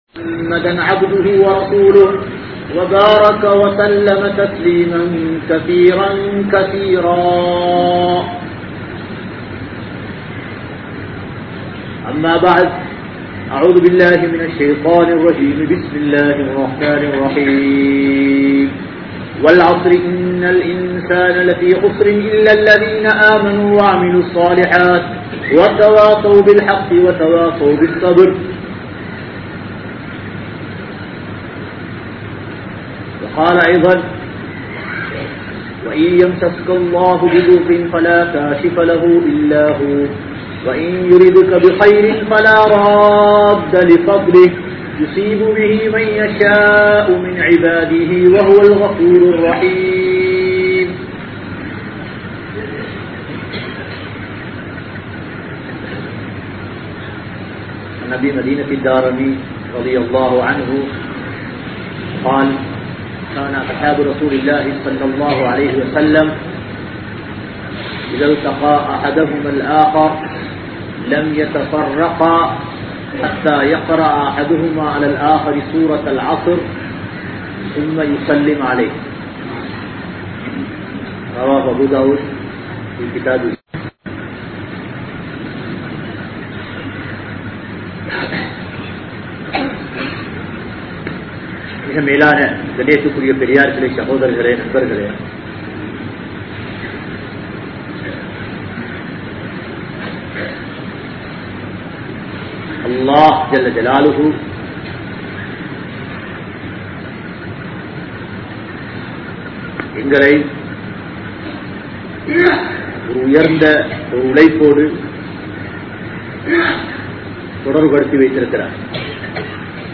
Dhauwath Entraal Enna? (தஃவத் என்றால் என்ன?) | Audio Bayans | All Ceylon Muslim Youth Community | Addalaichenai
Colombo, GrandPass Markaz